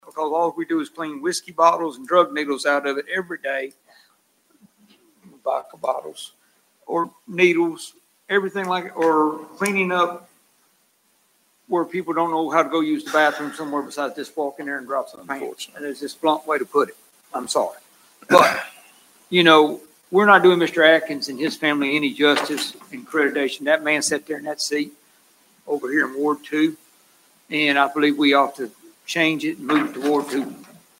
Mayor Knight said that the existing Fred Atkins Park, next to the Coffee Connection in the pocket park on Main Street downtown, is currently locked up.